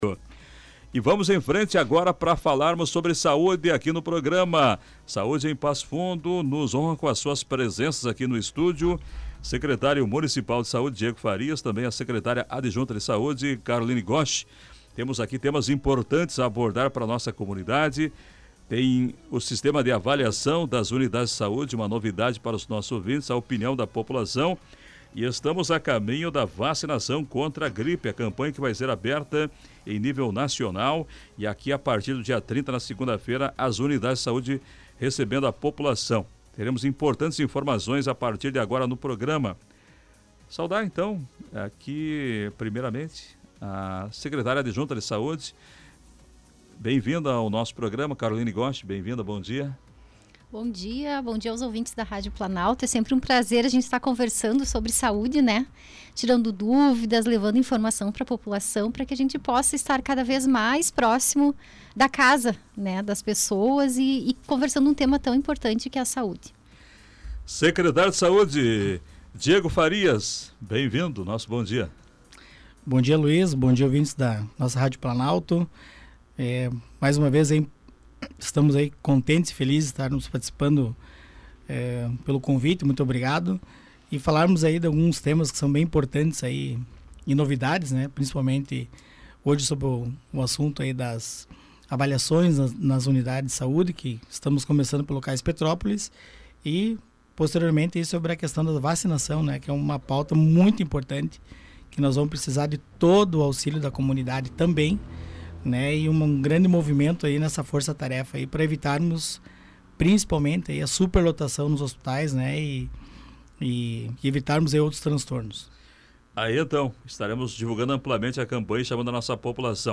Entrevista: pesquisa sobre serviços e campanha de vacinação em destaque na Saúde de Passo Fundo
Estiveram no estúdio o secretário de Saúde, Diego Farias, e a secretária adjunta Caroline Gosch.